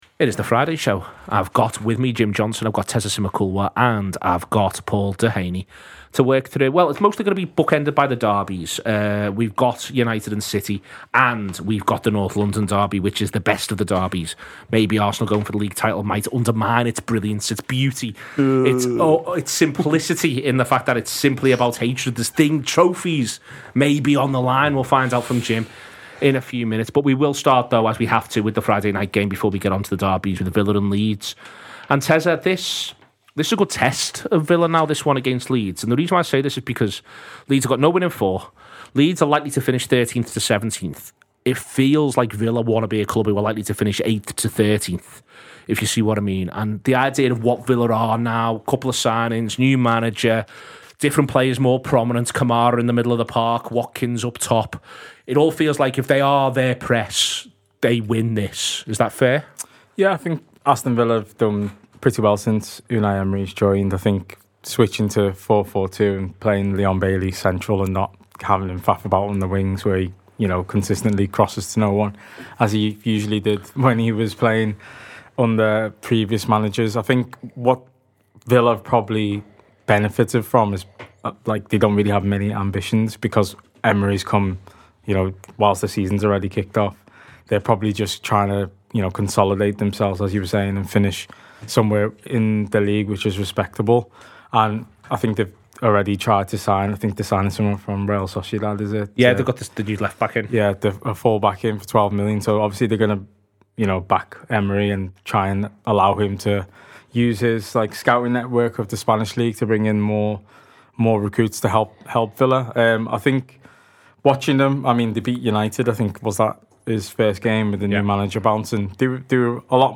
Fans preview the weekend’s Premier League action, with the Manchester and North London derbies likely to have a big impact at the top.